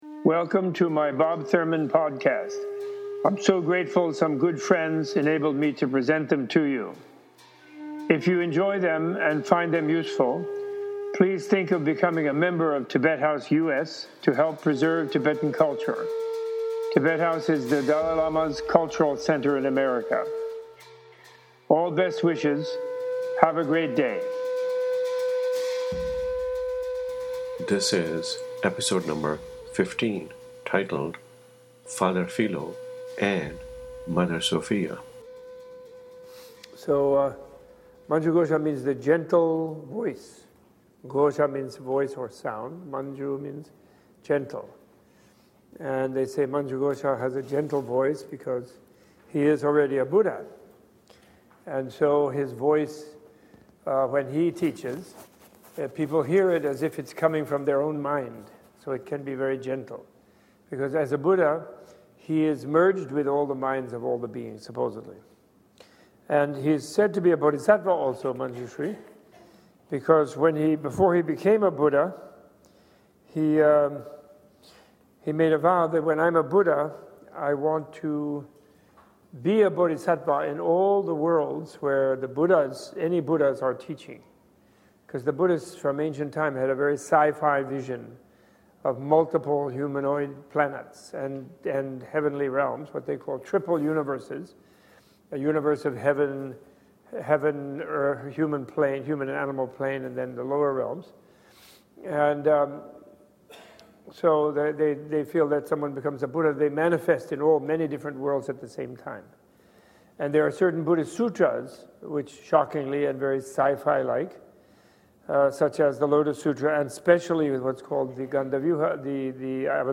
He elaborates on the role of Manjushri in influencing Tsongkhapa to eventually compose it, and in influencing those who would wish to study it, to understand it. Professor Thurman is reading from his own translation of the Essence of True Eloquence (drang nges legs bshad snying po), published as The Central Philosophy of Tibet.